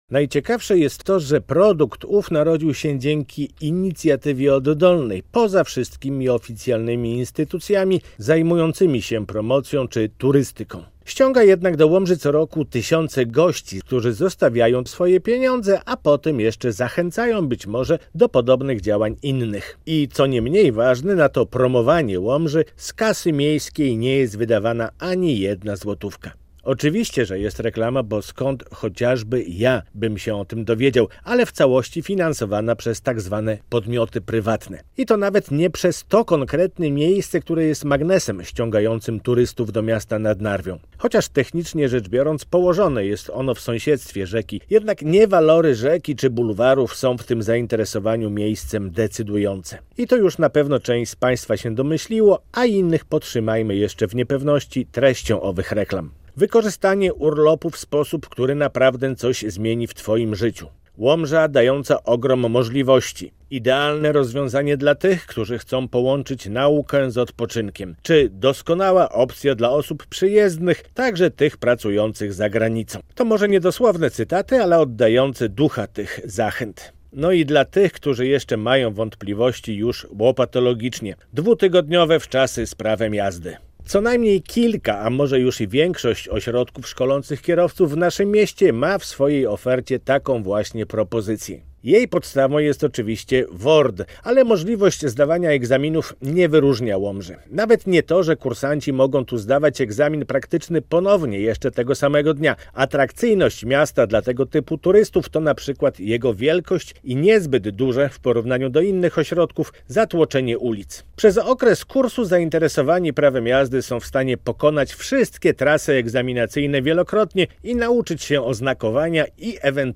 Radio Białystok | Felieton